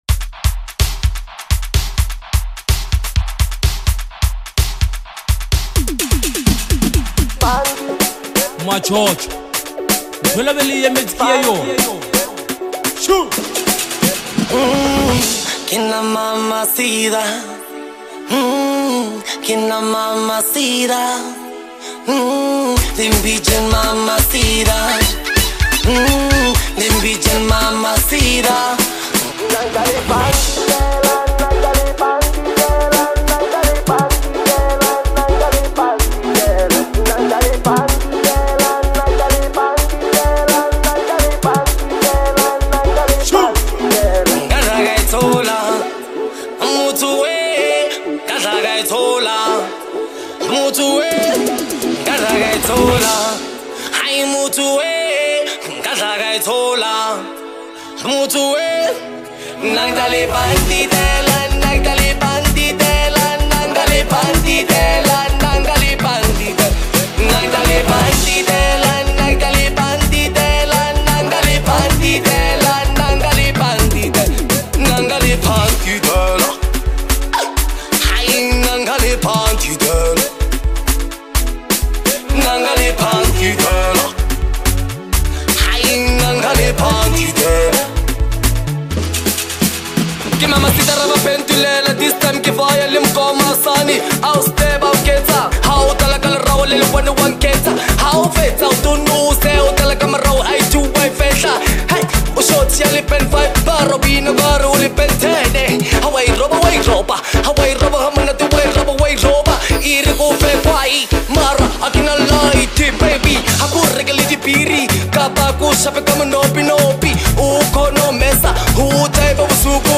a beat that hits hard and stays memorable